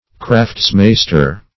Search Result for " craftsmaster" : The Collaborative International Dictionary of English v.0.48: Craftsmaster \Crafts"mas`ter\ (-m?s`t?r), n. One skilled in his craft or trade; one of superior cunning.
craftsmaster.mp3